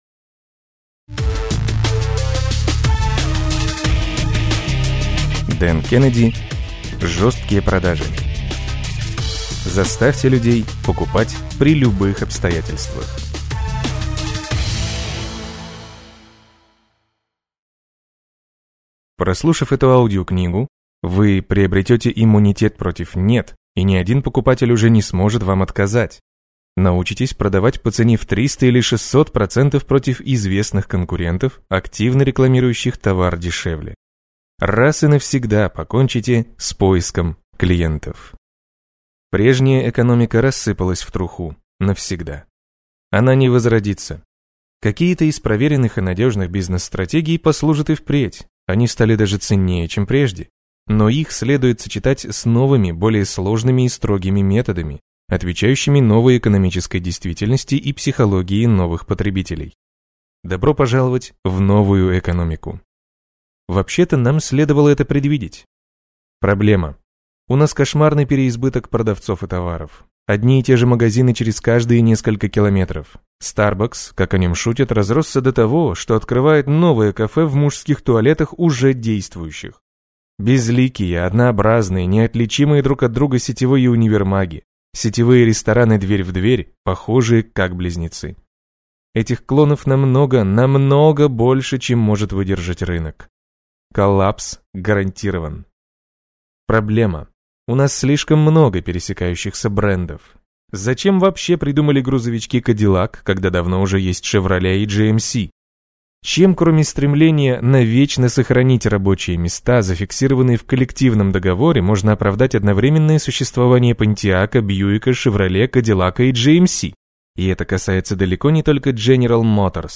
Аудиокнига Жесткие продажи | Библиотека аудиокниг